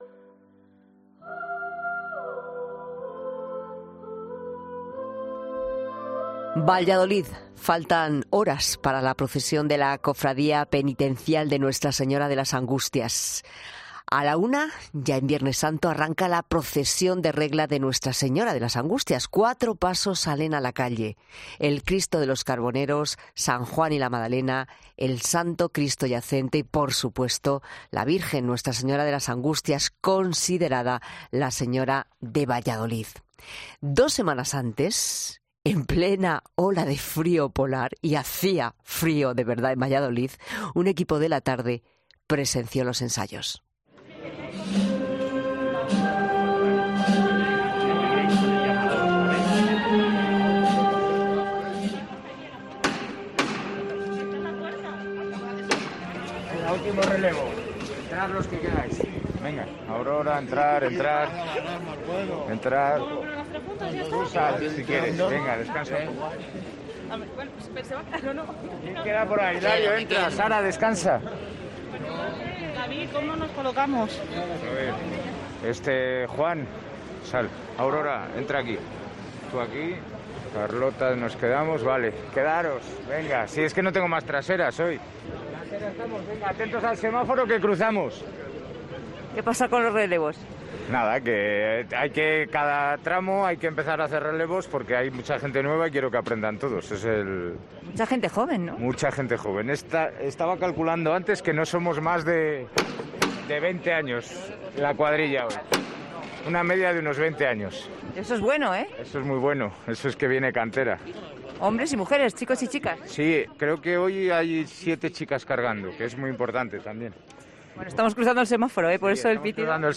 La Tarde de COPE acompaña a la Cofradía penitencial de Nuestra Señora de las Angustias de Valladolid para presenciar los ensayos del paso antes del...